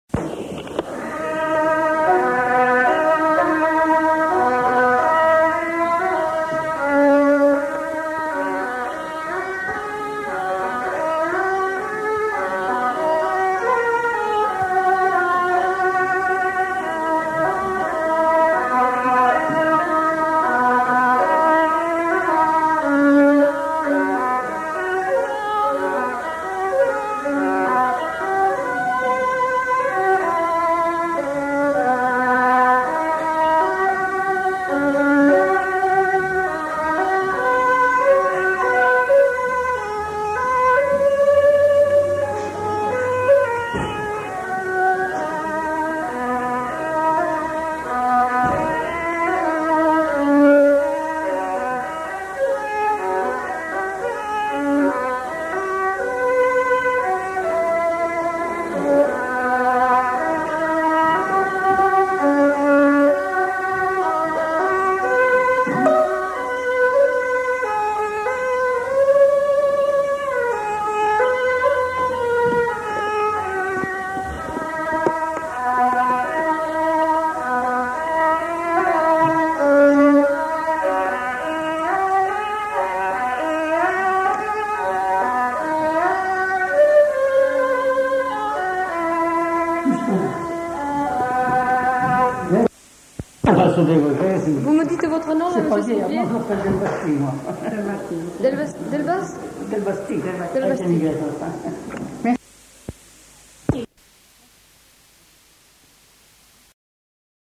Aire culturelle : Haut-Agenais
Lieu : Cancon
Genre : morceau instrumental
Instrument de musique : violon
Danse : valse